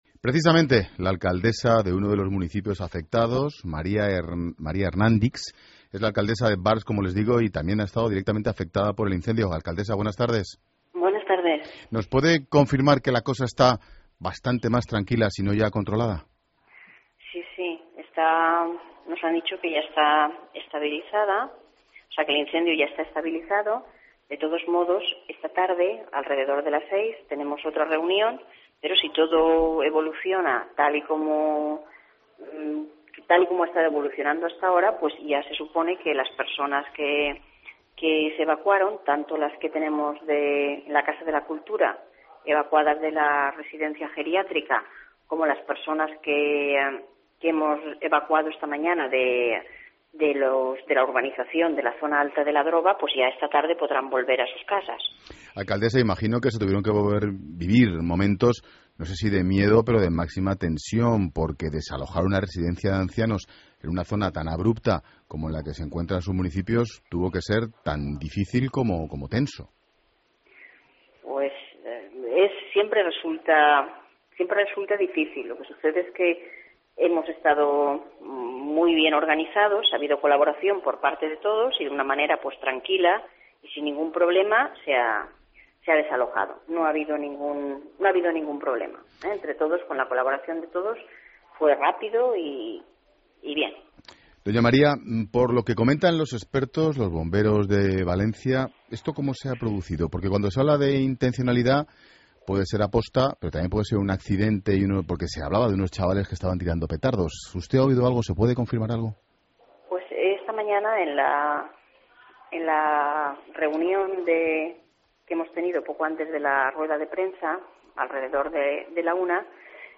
La alcaldesa de Barx (Valencia) María Hernandis ha afirmado en 'Mediodía COPE' que las personas desalojadas podrán volver a sus casas esta tarde.